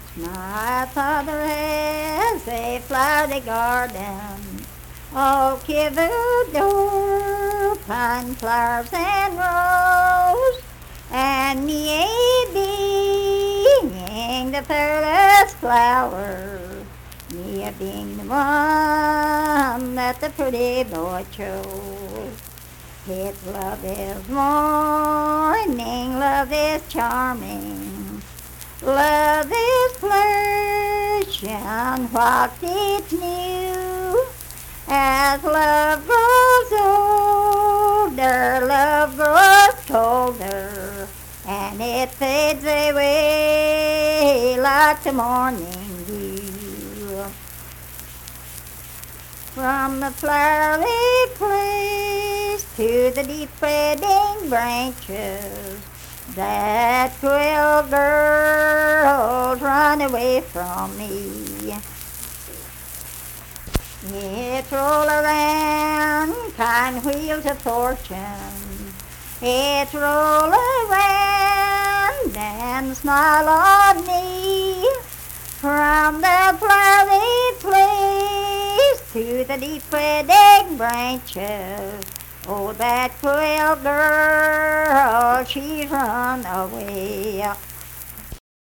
Unaccompanied vocal music performance
Verse-refrain 2(4).
Voice (sung)